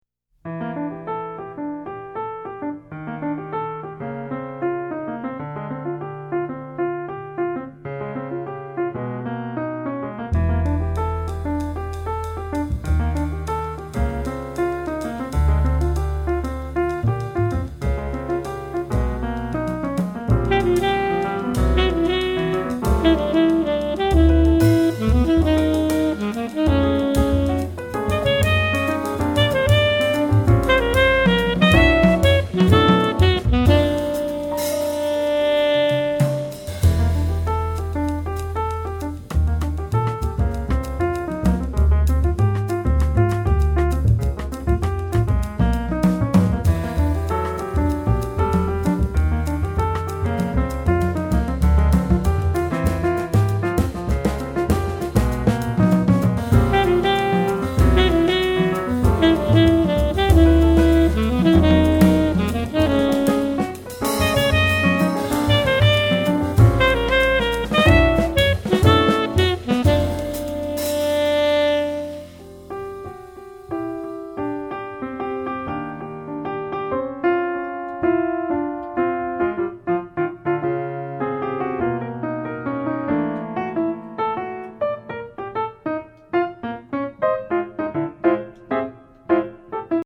Jazz
Tenor saxophonist
piano
bass